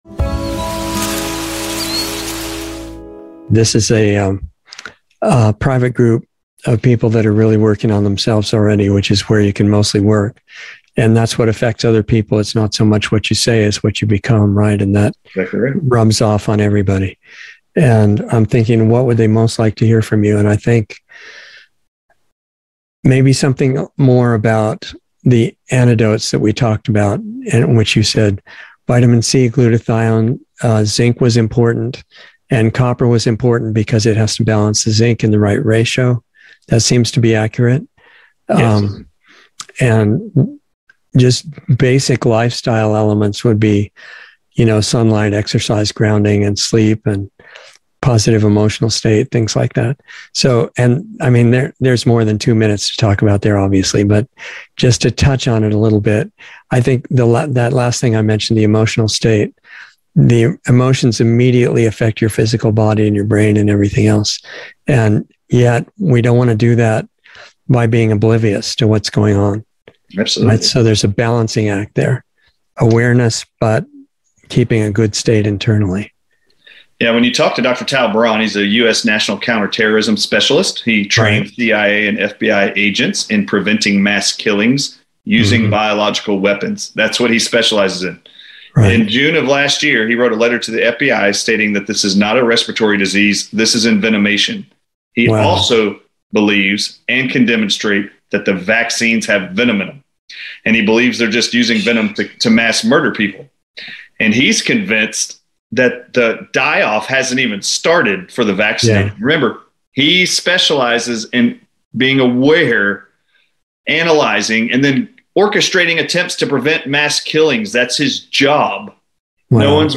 Insider Interview 6/9/22